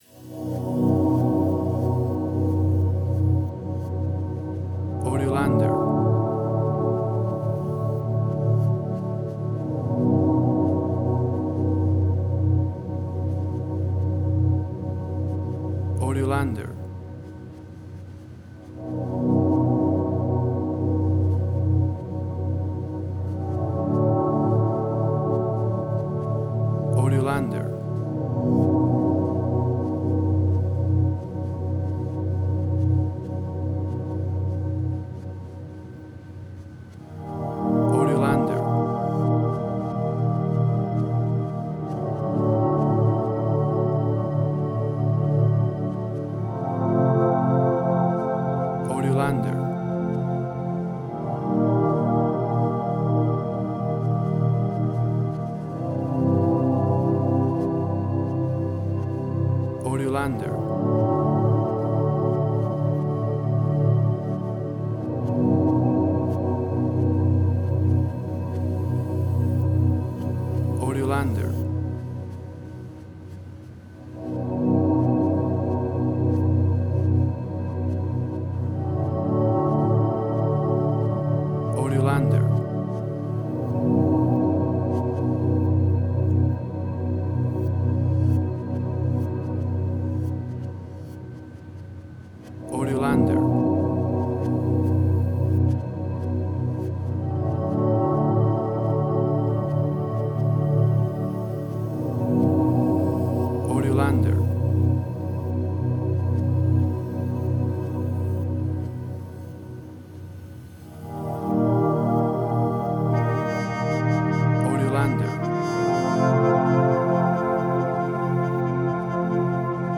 Modern Film Noir.
WAV Sample Rate: 16-Bit stereo, 44.1 kHz